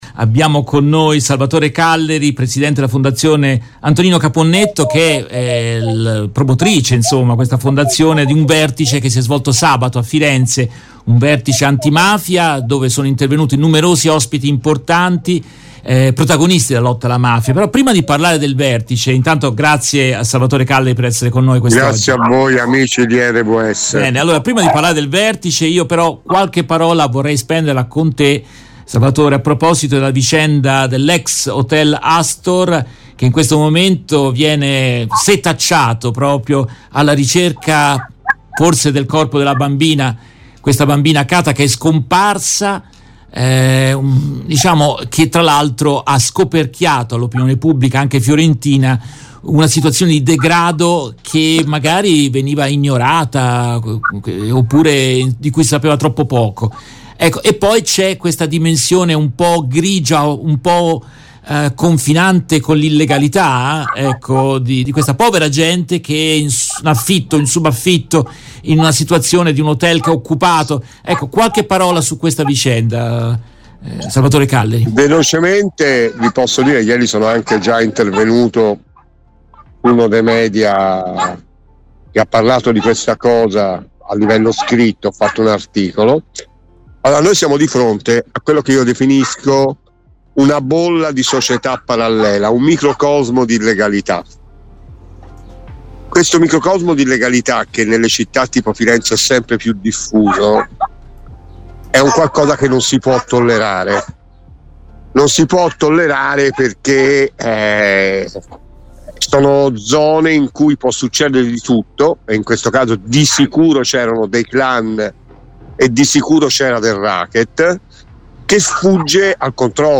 In questa intervista tratta dalla diretta RVS del 19 giugno 2023